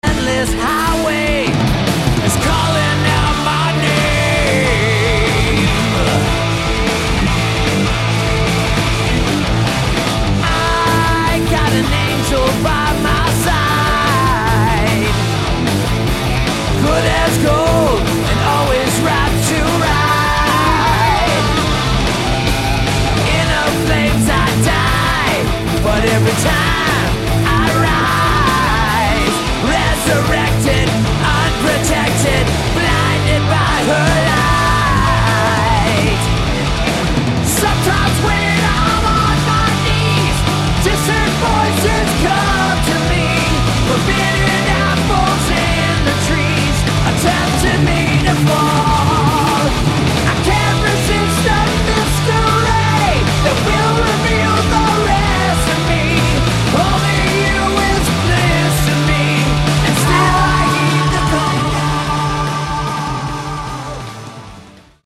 Drums
Melodic Hard Rock never sounded better.